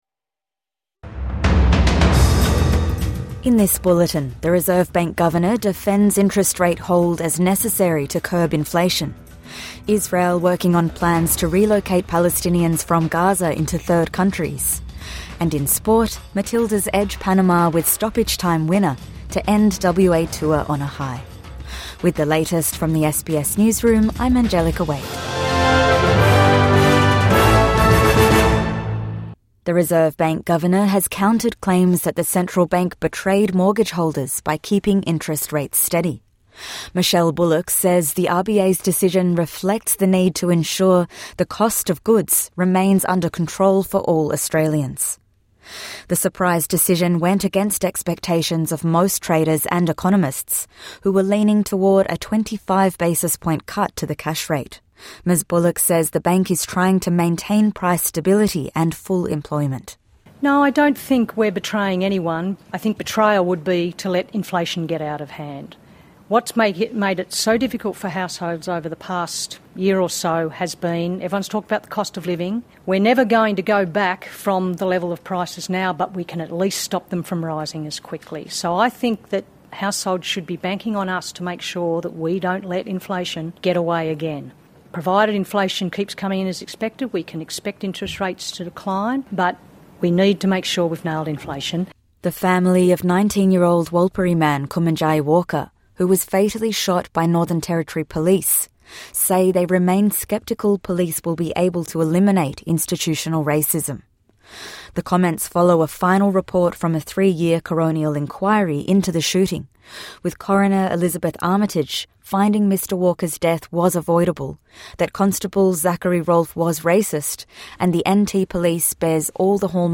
Reserve Bank defends its interest rates decision | Morning News Bulletin 9 July 2025